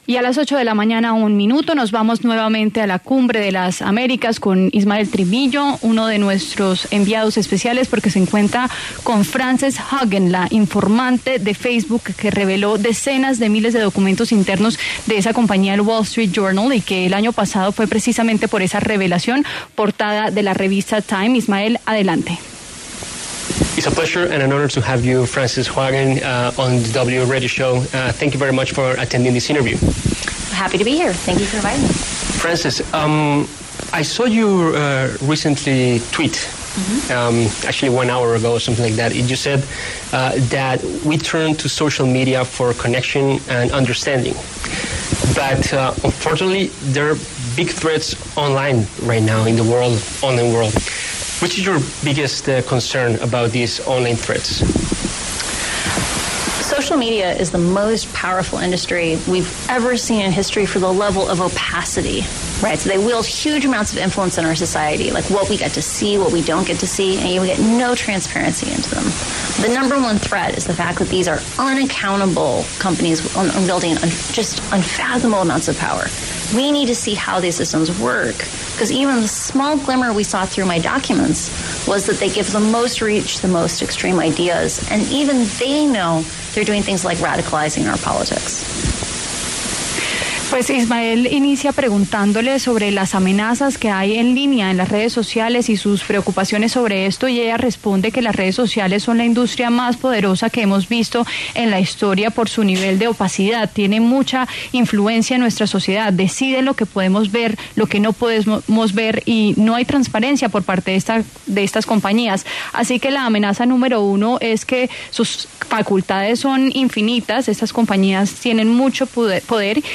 Frances Haugen, exempleada de Facebook y ahora informante, habló desde la Cumbre de las Américas sobre los riesgos de las redes sociales.